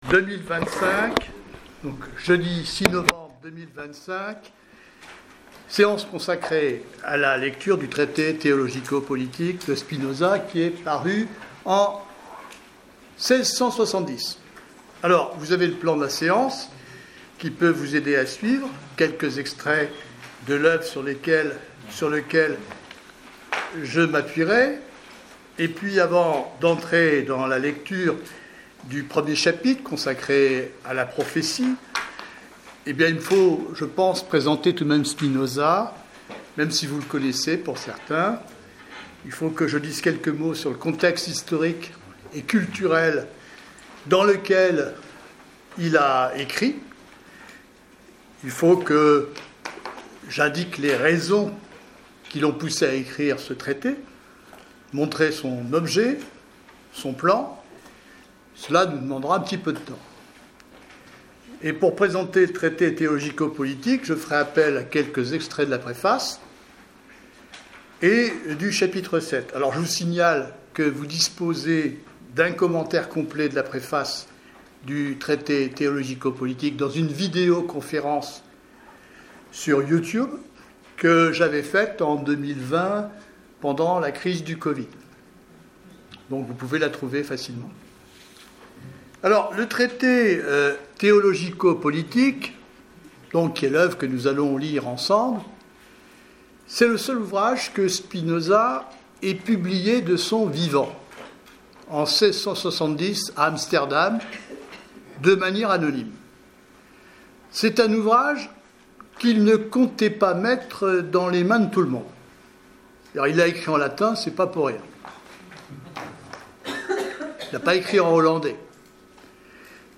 1/ Enregistrement de la séance du 06 novembre 2025